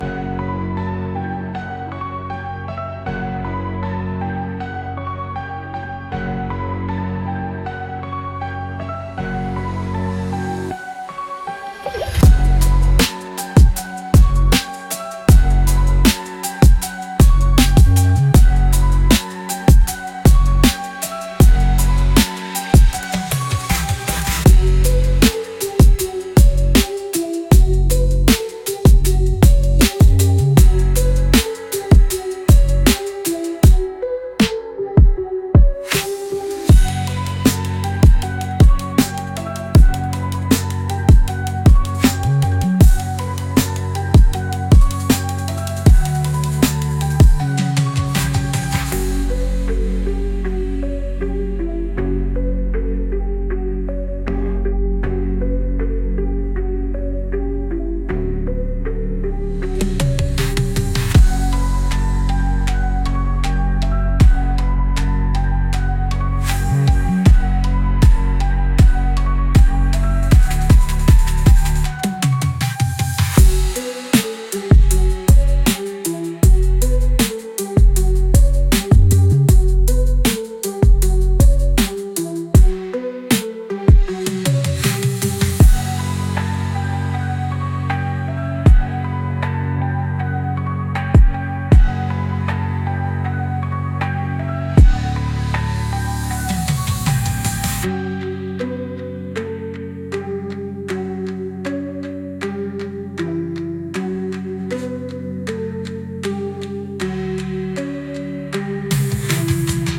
Instrumental - Real Liberty Media DOT xyz 1.50 - Grimnir Radio